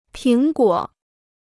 苹果 (píng guǒ): apple.